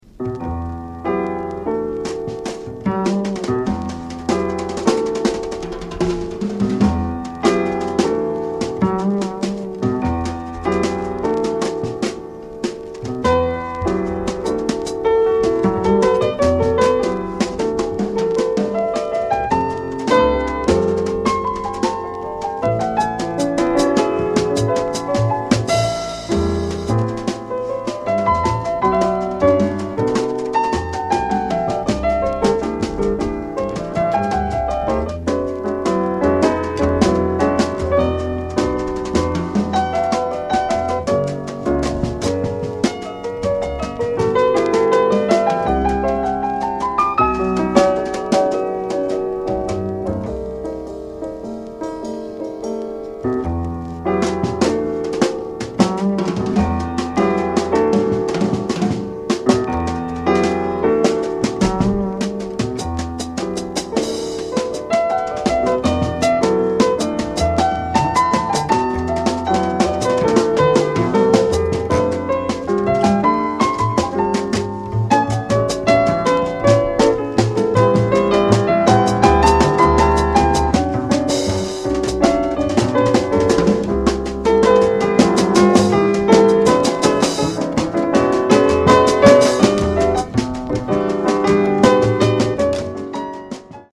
The only LP recorded as leader by this Irish Jazz pianist.